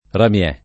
ramiè [ ram L$+ ]